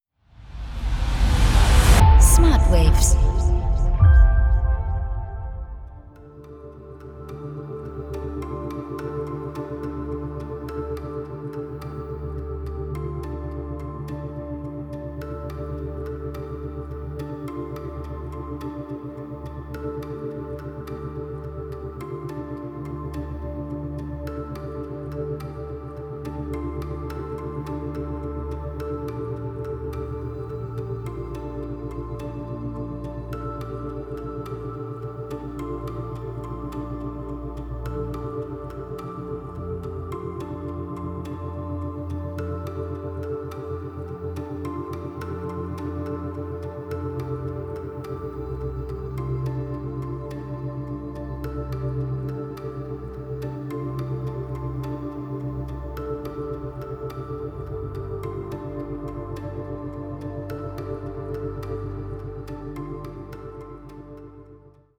4-8 Hertz Theta Wellen Frequenzen